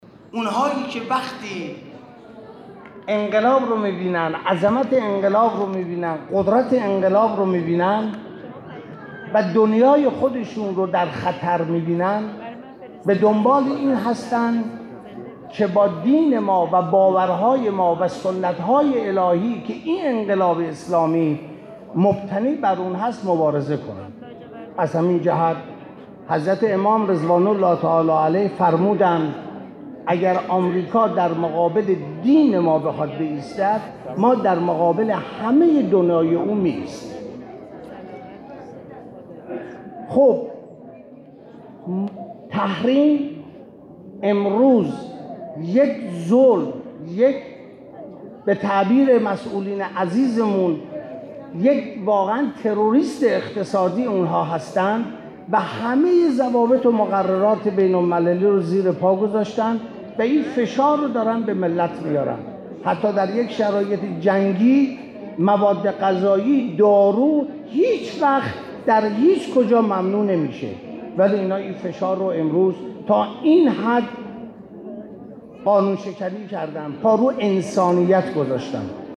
به گزارش خبرنگار سیاسی خبرگزاری رسا، مجمدباقر قالیباف عضو مجمع تشخیص مصلحت و سر لیست فهرست وحدت نیروهای انقلاب اسلامی، امروز عصر در تجمع جوانان انقلابی شهرری که در مسجد فیروزآبادی برگزار شد، گفت: انتخاب اصلح ضامن حل مشکلات کشور است.